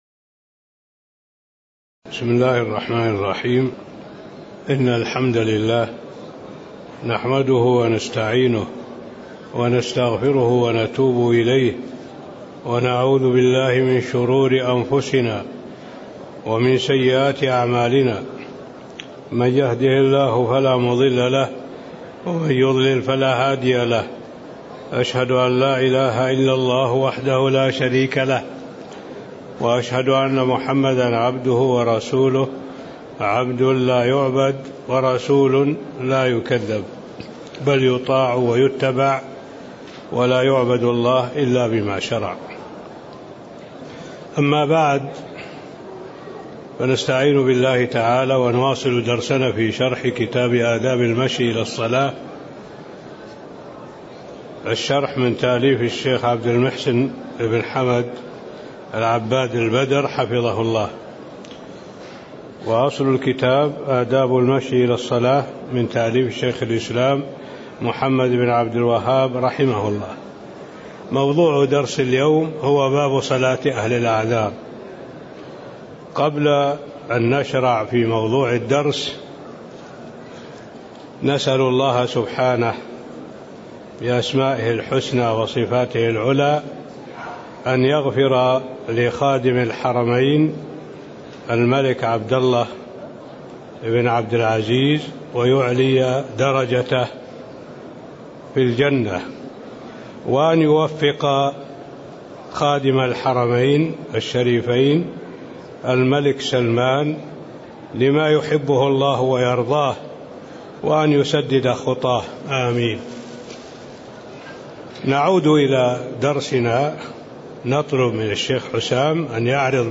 تاريخ النشر ٤ ربيع الثاني ١٤٣٦ هـ المكان: المسجد النبوي الشيخ: معالي الشيخ الدكتور صالح بن عبد الله العبود معالي الشيخ الدكتور صالح بن عبد الله العبود باب صلاة أهل الأعذار (19)